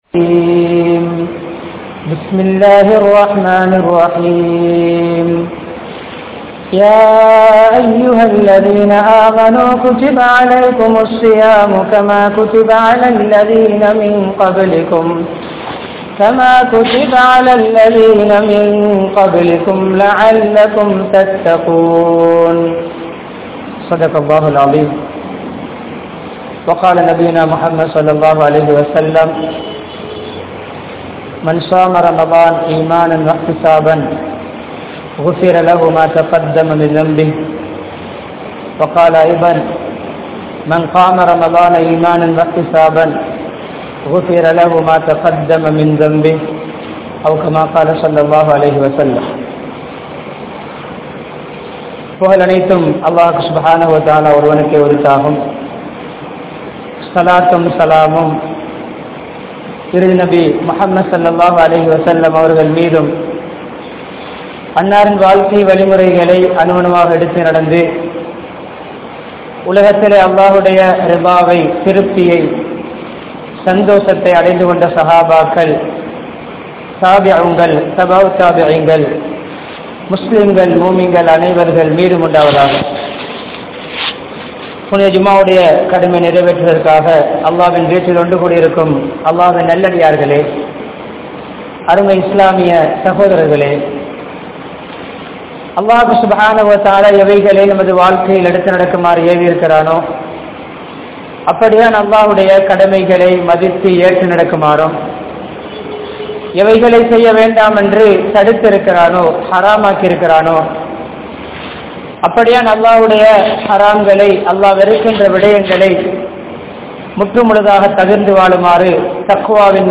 Ramalaanum Natkunamum (ரமழானும் நற்குனமும்) | Audio Bayans | All Ceylon Muslim Youth Community | Addalaichenai
Warasamull Jumma Masjidh